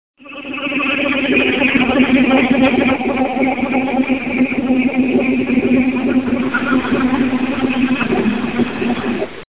EDIT: My graphics card fan occasionally makes the following niose when i first switch the computer on, but the noise stops after about 30 seconds or so.
This is the noise.
horrible sound from inside computer.mp3 - 149.7 KB - 264 views